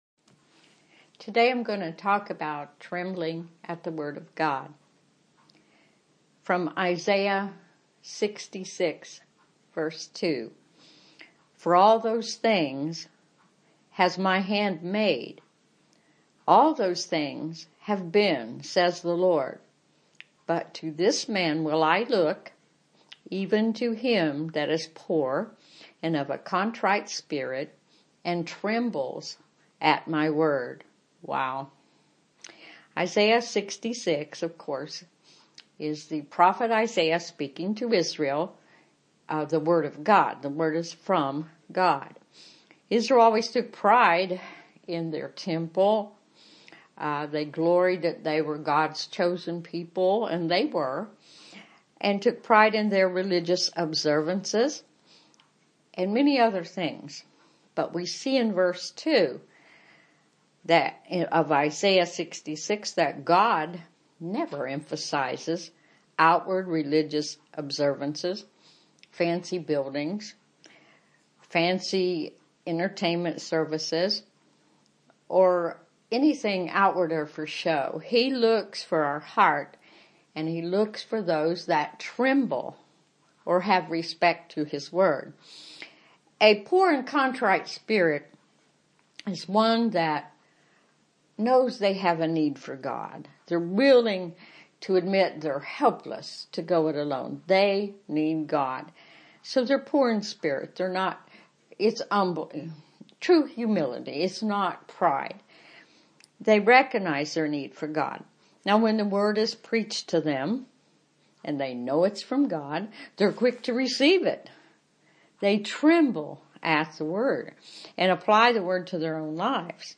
Message given on April 13, 2008 (link to audio version)